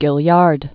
(gĭl-yärd, gālärd) Formerly Cu·le·bra Cut (k-lābrə)